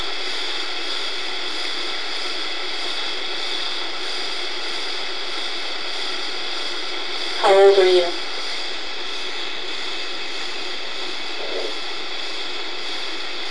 This recording was captured in the bathroom of our guest room, on Sunday night about 9:00 pm.
It appears to be an adult male answering "SEVEN" when I ask "How old are you?".  I found this disturbing because it's obvious by the sound of the voice that this energy is not seven years old, but much older.
bathroomvoice.wav